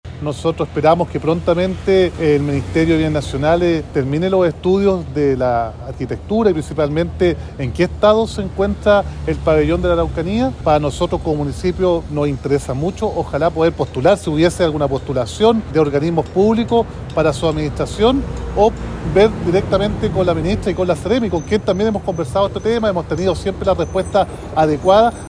Respecto a este punto, se manifestó el alcalde de Temuco, Roberto Neira, en primera instancia, lamentando la cantidad de tiempo que el recinto permanece cerrado y en segundo punto, indicando que el municipio tiene interés en postular a su administración.